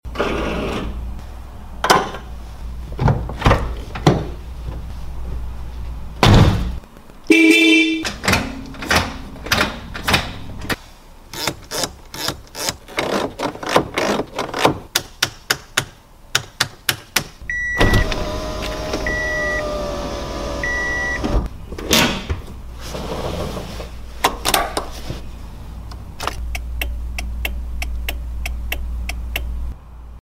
Sounds of Honda Prelude 1987 sound effects free download